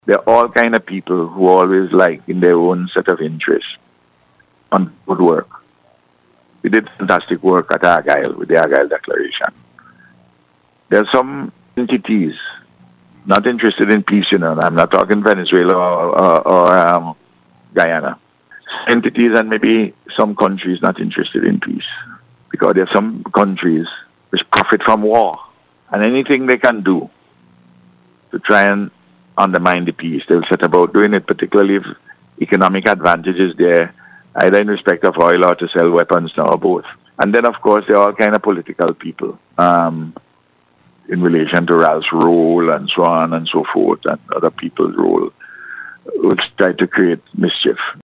Speaking on radio yesterday, the Prime Minister explained that the photograph was taken in 2022 at an event commemorating the life and work of Simon Bolivar.